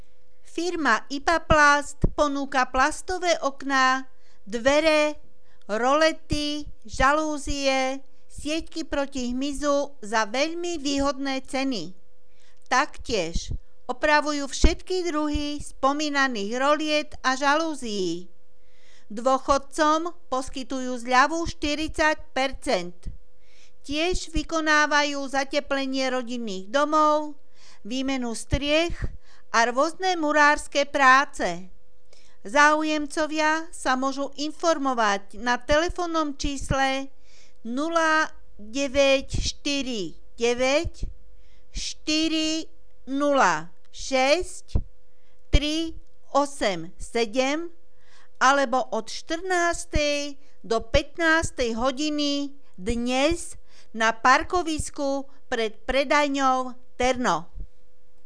Hlásenia miestneho rozhlasu 12.6.2017 (Komerčné hlásenie IPA PLAST)